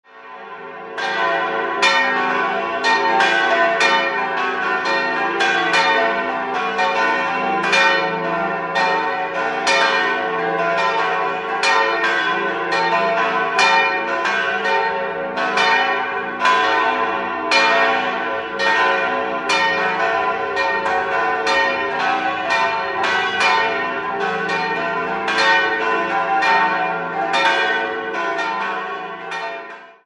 6-stimmiges Geläut: des'-f'-g'-as'-b'-des''
Im Jahr 2010 wurde das Geläut saniert und mit zwei neuen Glocken aus der Gießerei Bachert ergänzt.